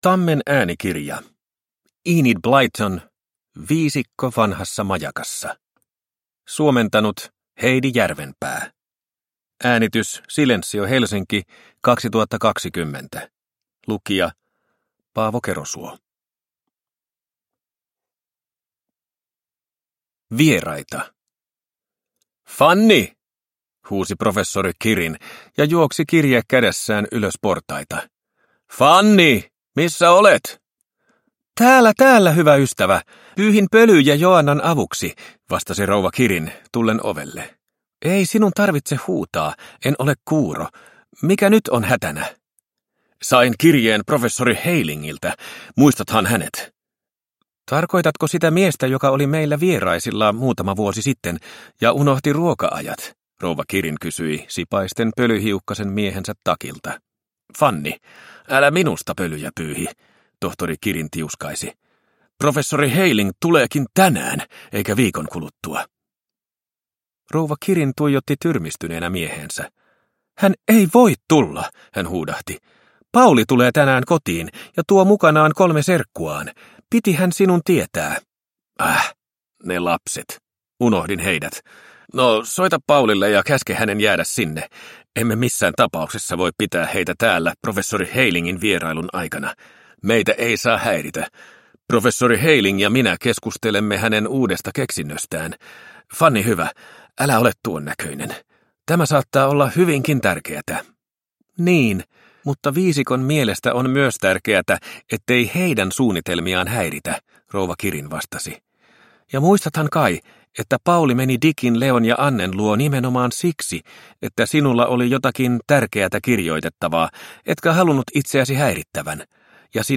Viisikko vanhassa majakassa – Ljudbok – Laddas ner